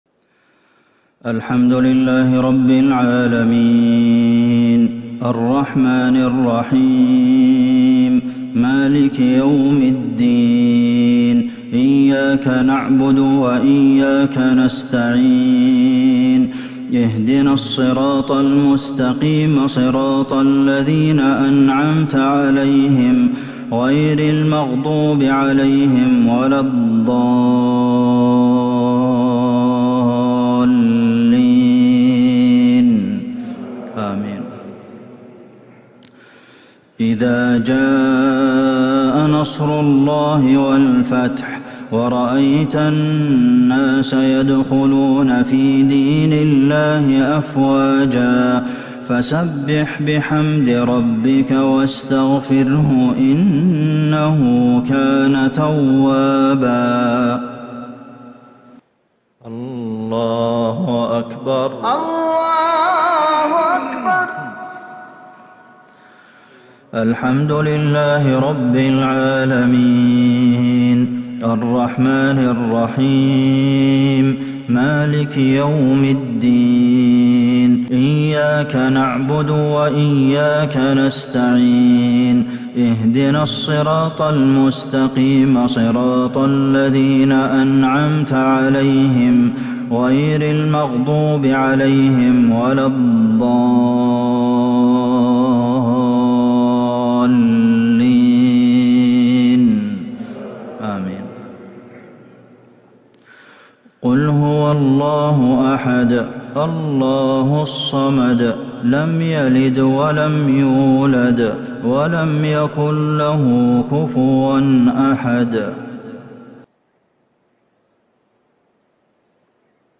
صلاة الجمعة 5-4-1442هـ سورتي النصر والإخلاص | Jumua prayer Surah An-Nasr and Al-Ikhlas 20/11/2020 > 1442 🕌 > الفروض - تلاوات الحرمين